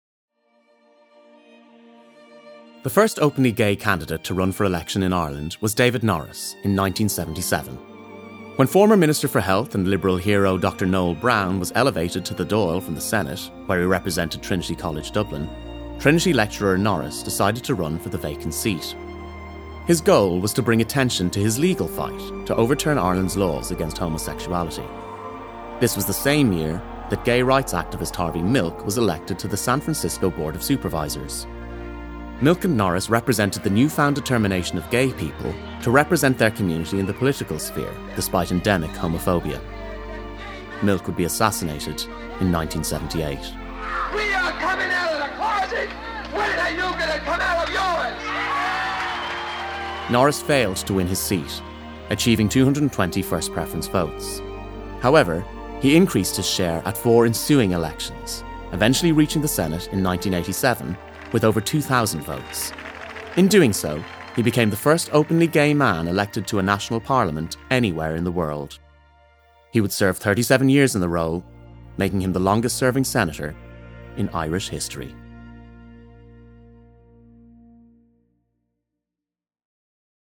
20s-30s. Male. Studio. Irish.
Documentary